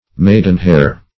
maidenhair \maid"en*hair`\, maidenhair fern \maidenhair fern\,